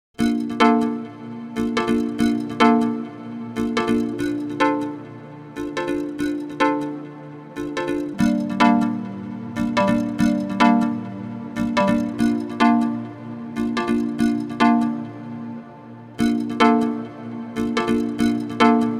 It's pretty good at string-like and marimba-like sounds and pairs well with a dash of reverb after it.
Break run through it